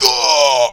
PlayerKnockDown.wav